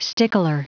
Prononciation du mot stickler en anglais (fichier audio)
Prononciation du mot : stickler